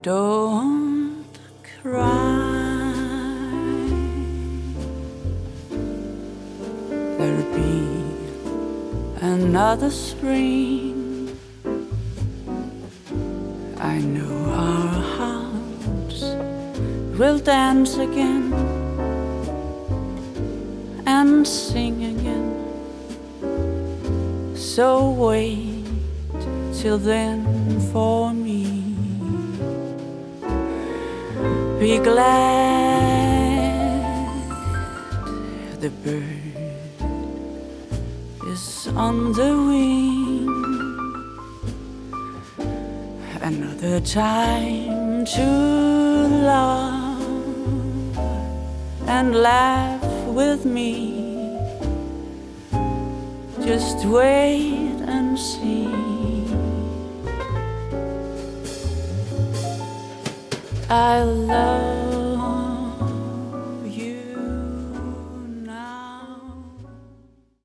• Jazz
• Viser
Vokal
Klaver
Duo